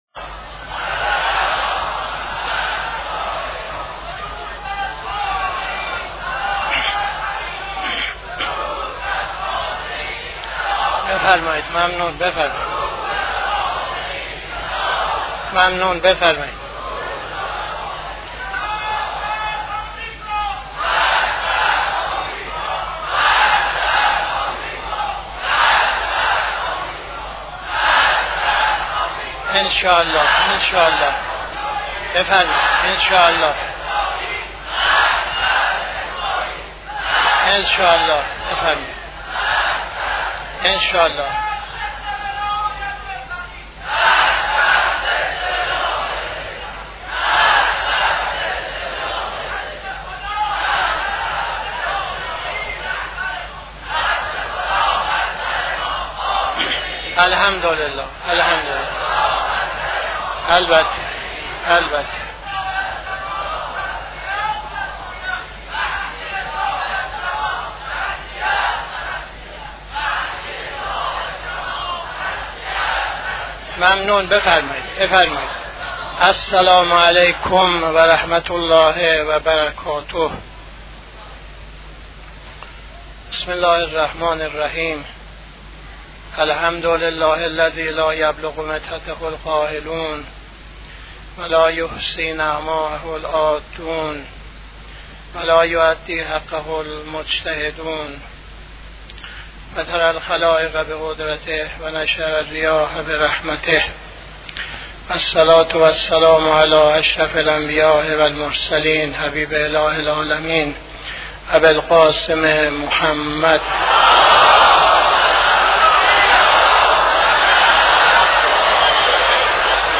خطبه اول نماز جمعه 03-11-82